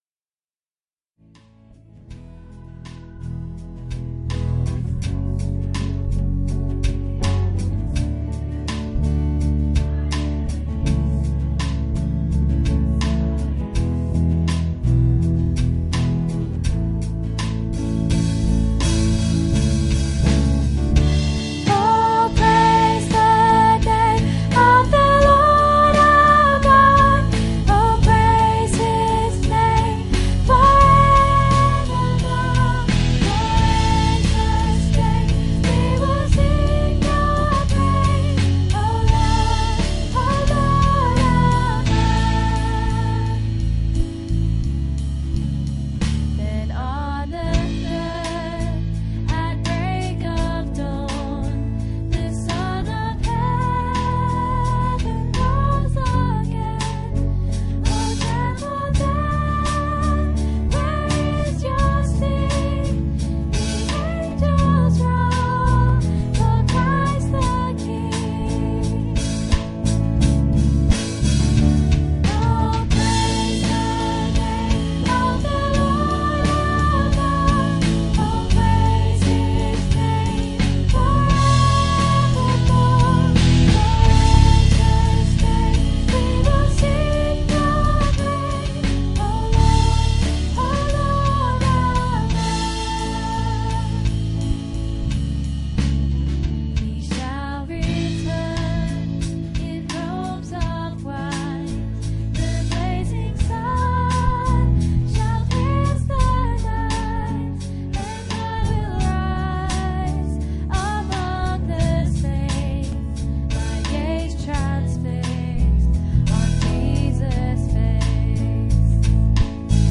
Service Type: Sunday Church